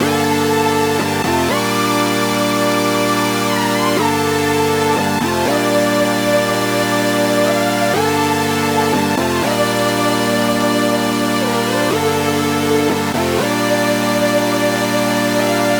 MELODY LOOPS
(121 BPM – Gm)